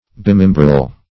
Search Result for " bimembral" : The Collaborative International Dictionary of English v.0.48: Bimembral \Bi*mem"bral\, a. [L. bis twice + membrum member.]
bimembral.mp3